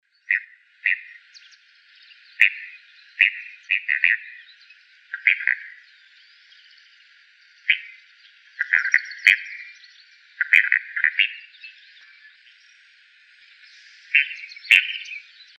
Frango-d´água-carijó (Porphyriops melanops)
Nome em Inglês: Spot-flanked Gallinule
Localidade ou área protegida: Reserva Ecológica Costanera Sur (RECS)
Condição: Selvagem
Certeza: Fotografado, Gravado Vocal
Recs05.Pollona-pintada.mp3-2-(2).mp3